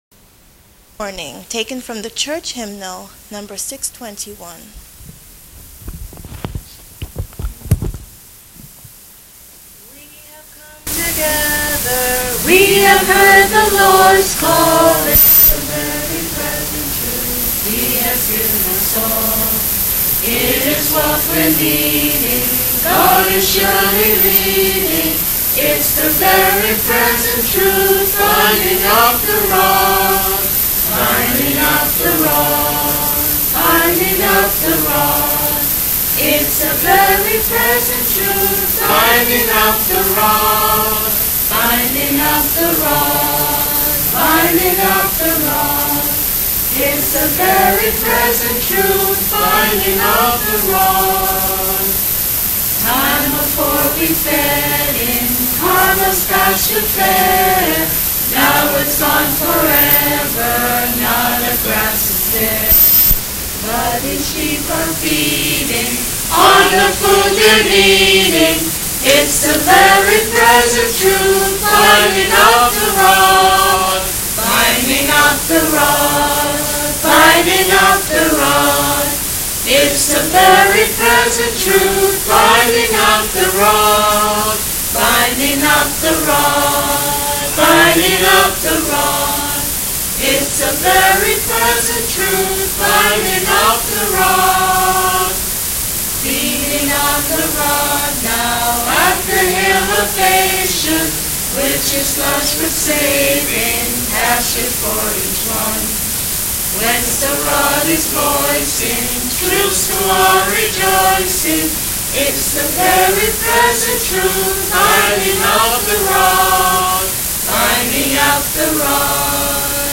Sung without instrumental accompaniment